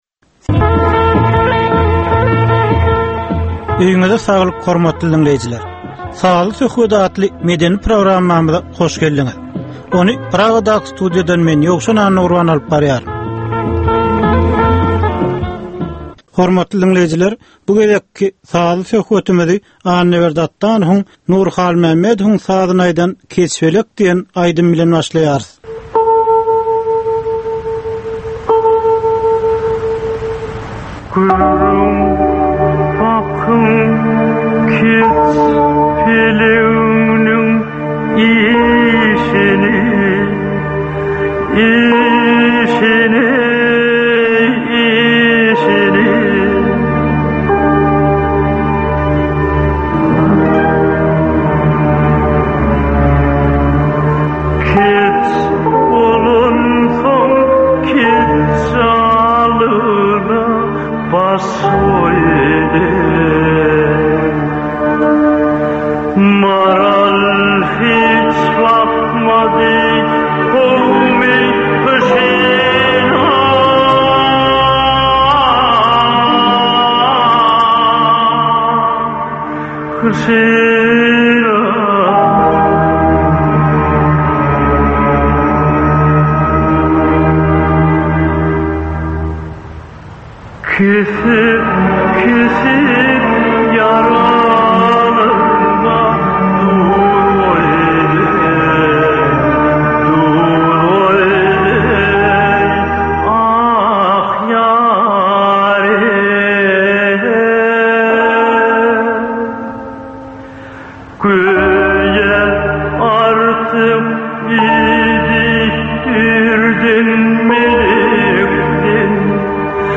Türkmeniň käbir aktual meseleleri barada 30 minutlyk sazly-informasion programma.